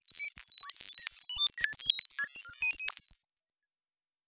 Sci-Fi Sounds / Electric / Data Calculating 3_4.wav
Data Calculating 3_4.wav